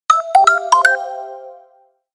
Jumpingnotes.ogg